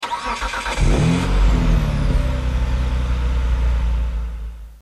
Home gmod sound vehicles tdmcars eclipsegt
enginestart.mp3